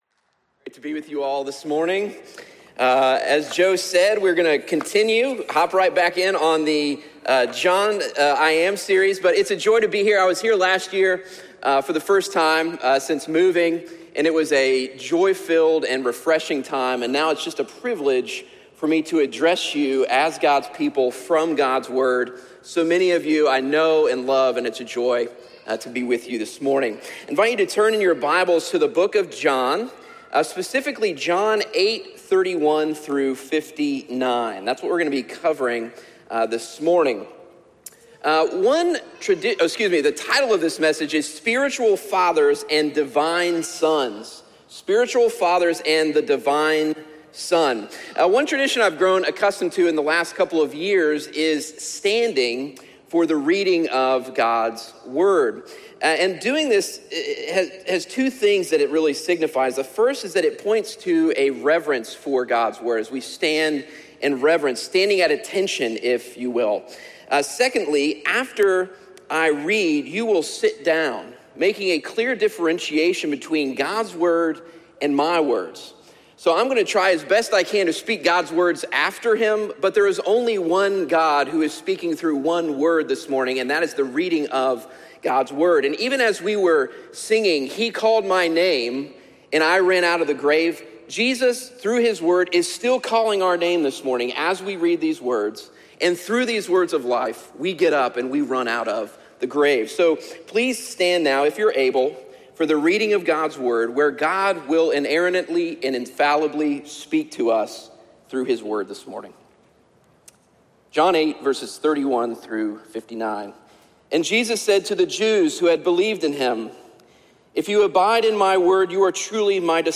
Message: “A Savior for All People”